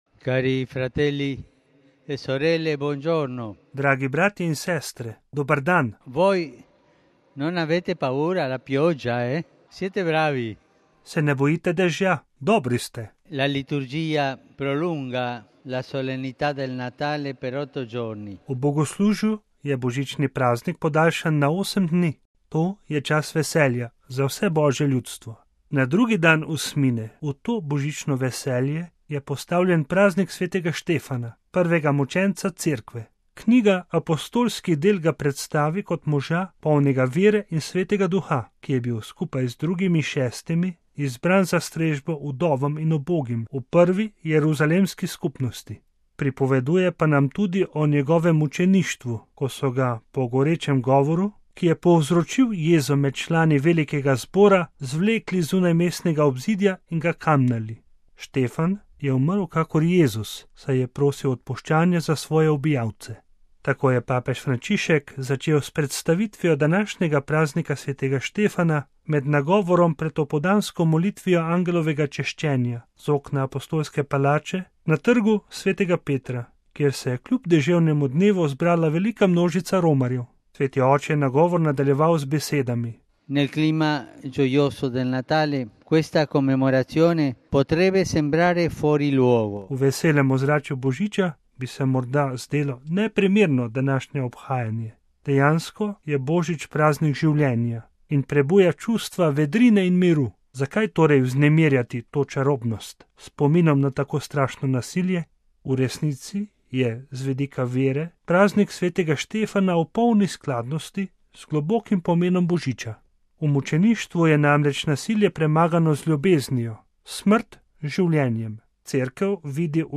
Tako je papež Frančišek začel s predstavitvijo današnjega praznika sv. Štefana med nagovorom pred opoldansko molitvijo Angelovega češčenja z okna apostolske palače na Trgu sv. Petra, kjer se je kljub deževnemu dnevu zbrala velika množica romarjev.